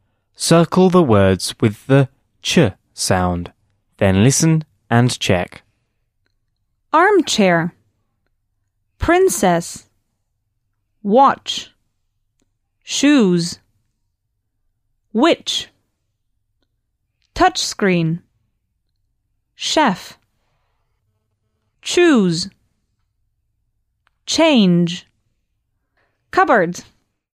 Circle the words with the /t∫/ sound.